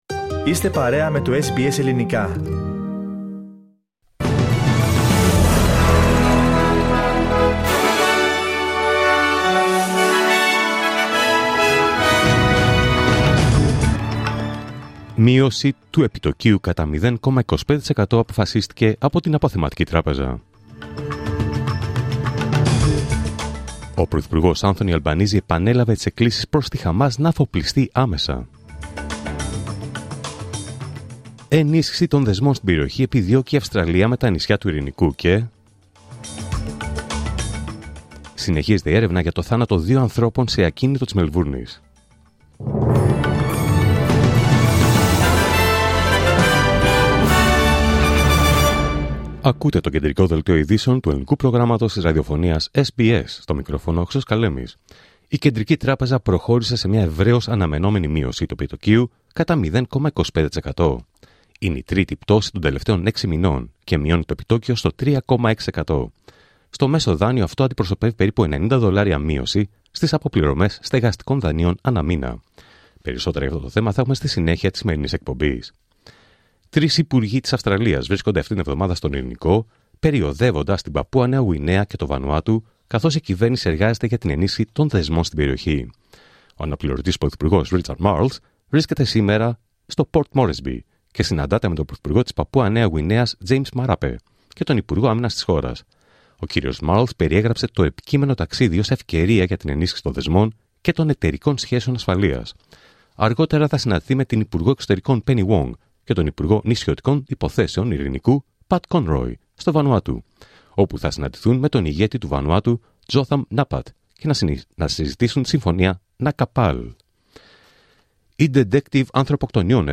Δελτίο Ειδήσεων Τρίτη 12 Αυγούστου 2025